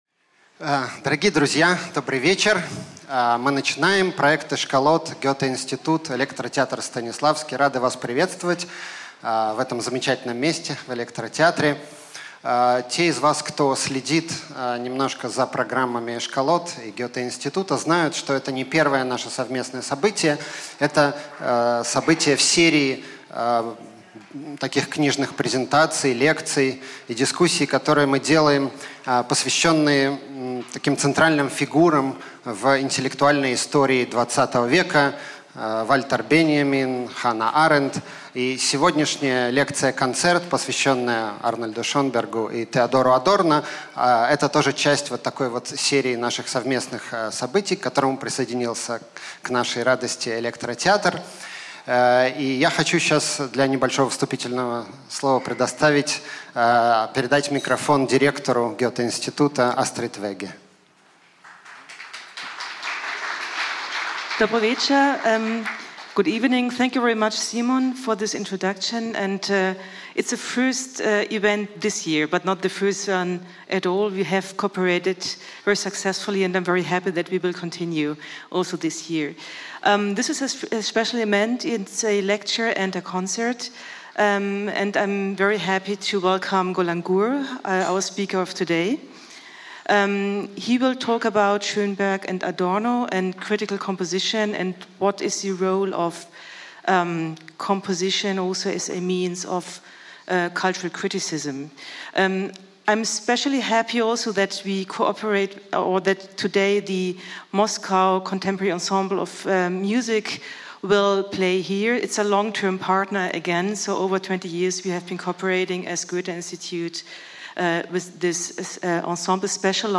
Аудиокнига Шенберг/Адорно: рождение критической музыки | Библиотека аудиокниг